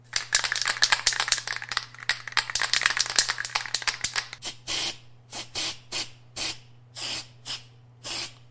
Граффити спрей: баллончик трясут и краску распыляют